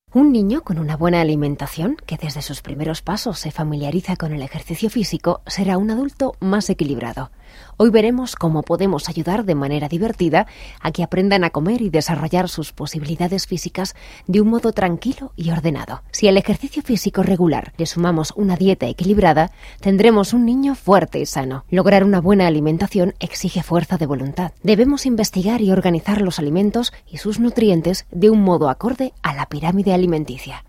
Voice over, locutora en castellano, voz femenina
kastilisch
Sprechprobe: Industrie (Muttersprache):